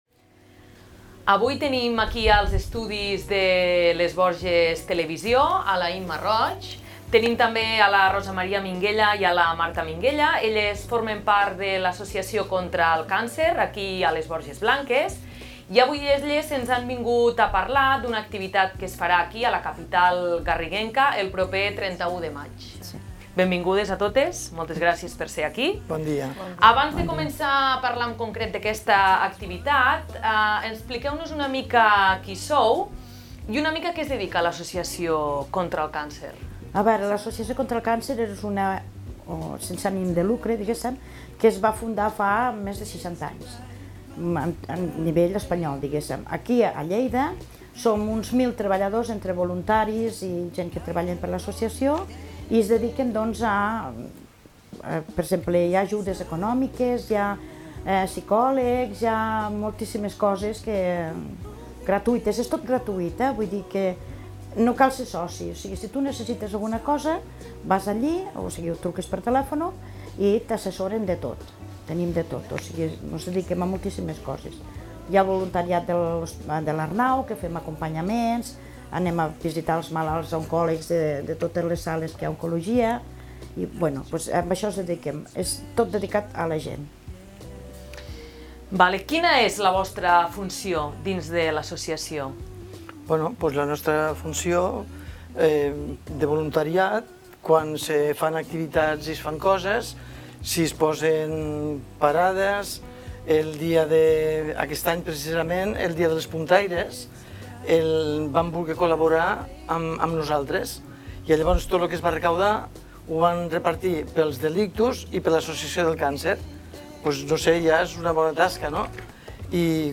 voluntàries de l'Associació Contra el Càncer de Lleida a les Borges Blanques.